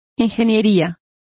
Also find out how ingenierias is pronounced correctly.